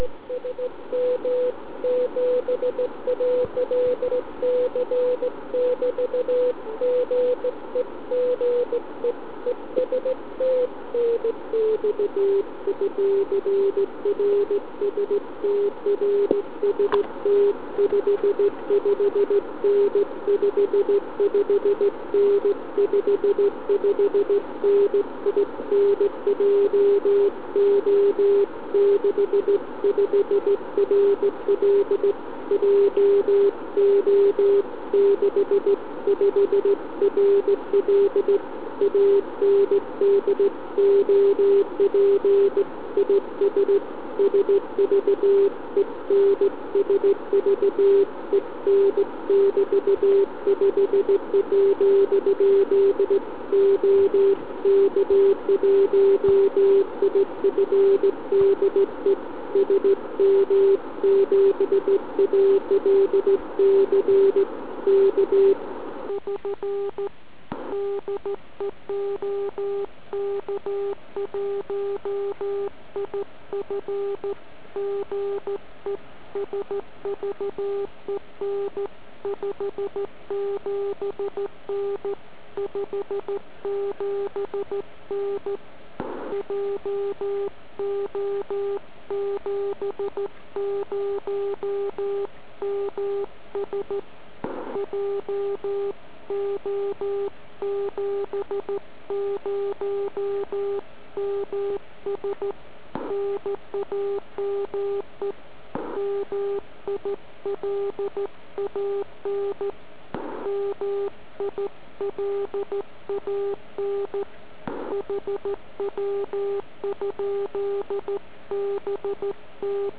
Moje Lambda �tvrt na �st�edn�m topen�, vyhovuje tak na m�stn� spojen�. P�esto jsem v�ak n�jak�m omylem v p�tek 21.12. zapnul FT817 v p�smu 144 MHz.